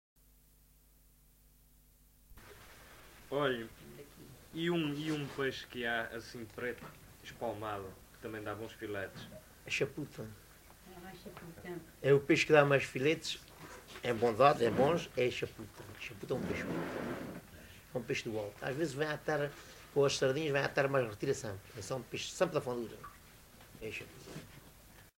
LocalidadeAlvor (Portimão, Faro)